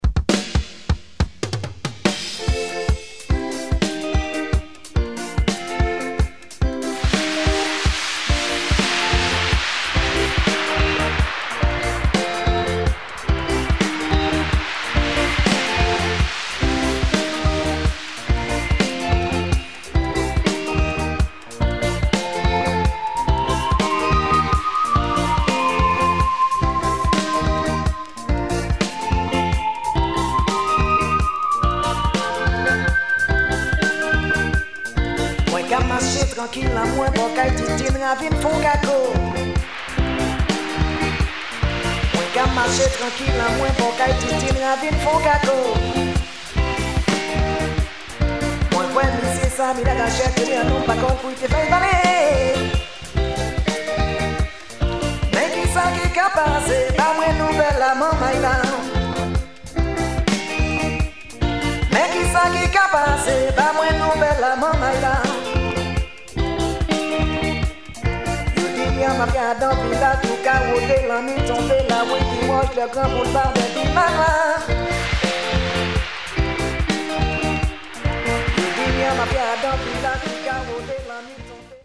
Soulful zouk